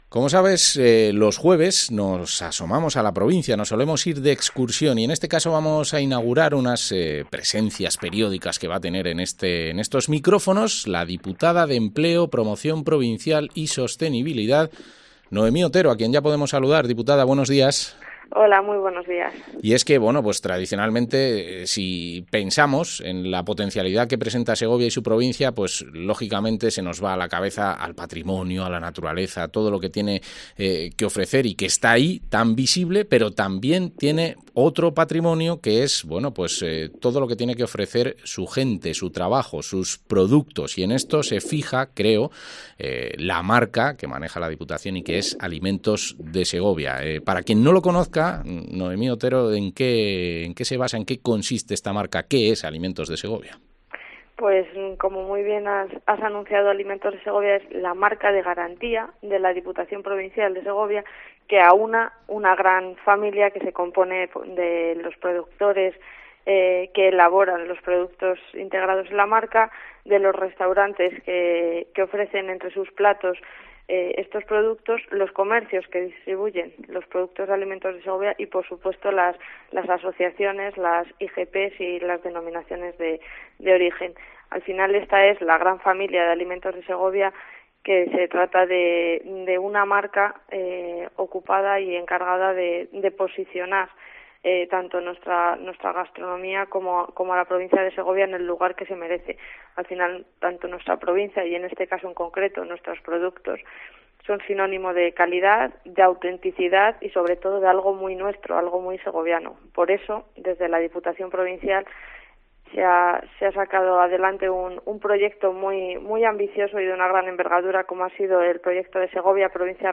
Entrevista a la diputada de Empleo, Promoción Provincial y Sostenibilidad, Noemí Otero